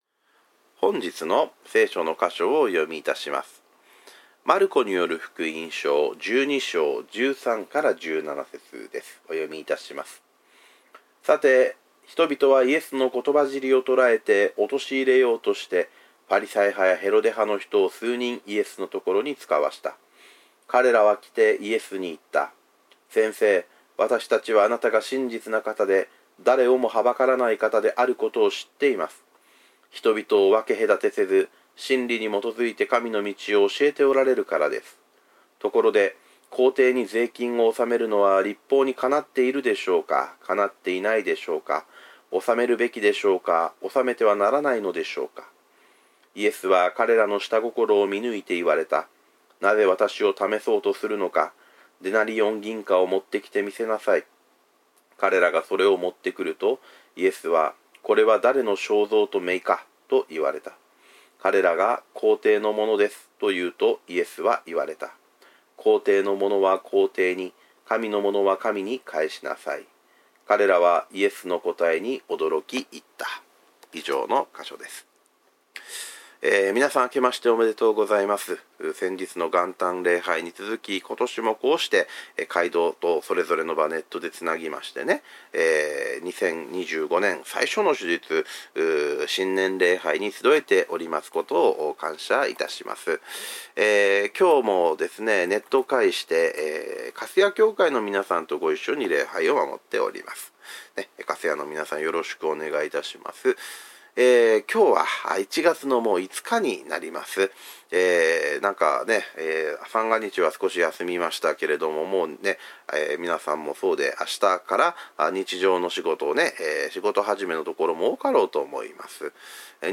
日々是好日: 今年始めの主日礼拝